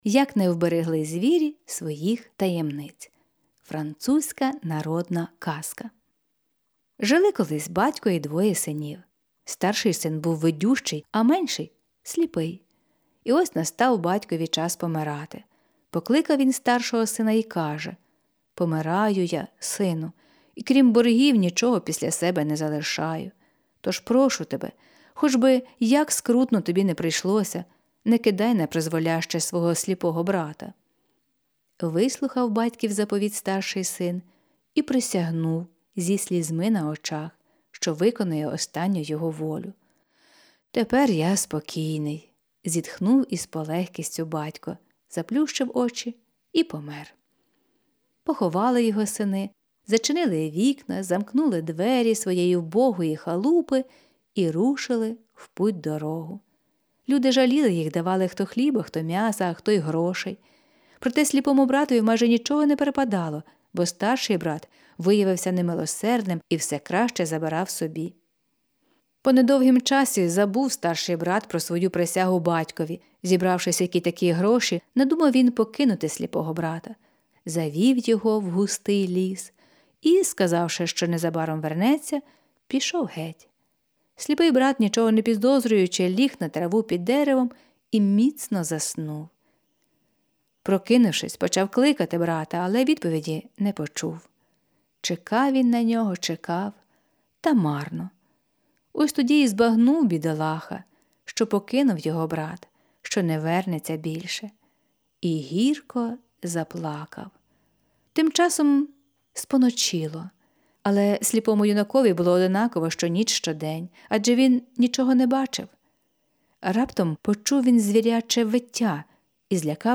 Жанр: Казка на добраніч Автор